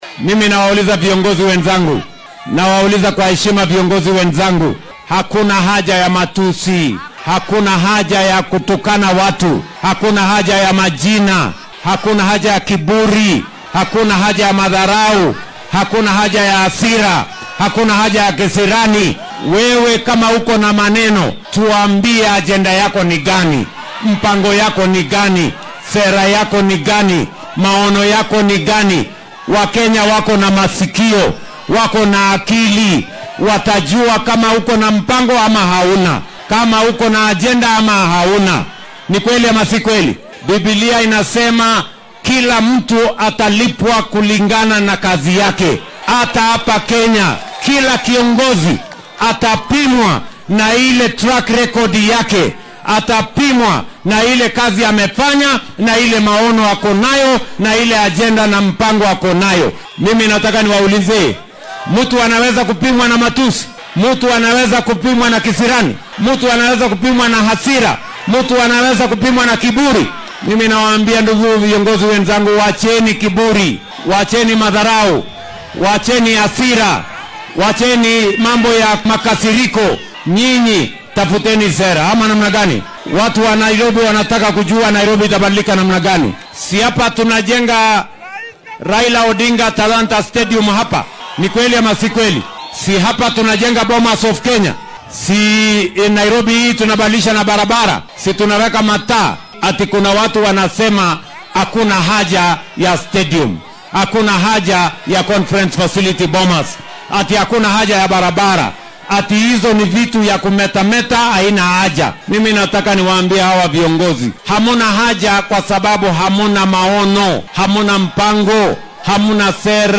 Madaxweynaha dalka William Ruto ayaa maanta difaacay qiimaha kordhay ee shidaalka . Xilli uu ku sugnaa kaniisadda AGC ee xaafadda Karen ee magaalada Nairobi ayuu arrintan u aaneeyay xaaladda dhaqaale ee waddanka iyo baahiyaha dhanka kaabeyaasha.